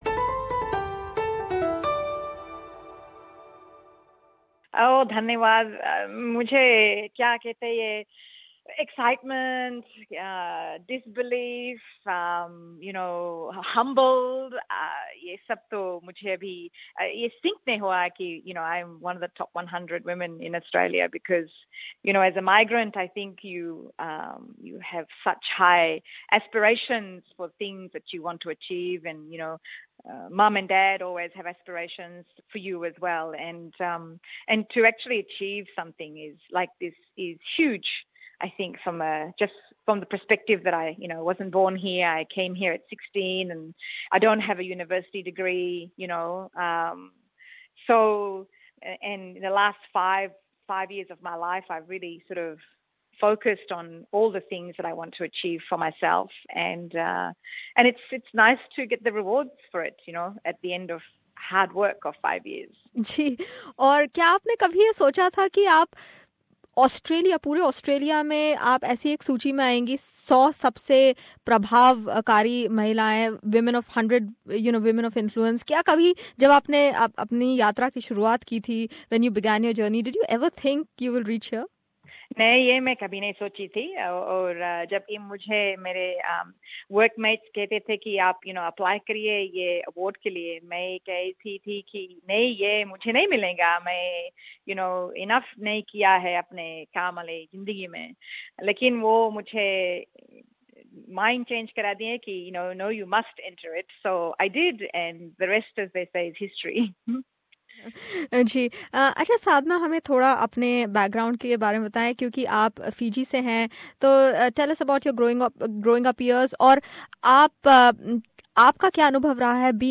Tune in for this free flowing chat